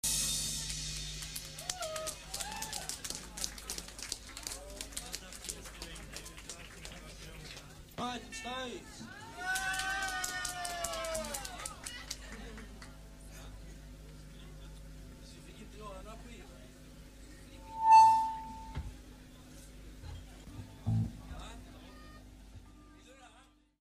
Flamman August 18, 1979
This was possible due to the Tandberg built in mixer of two mics with stereo line input.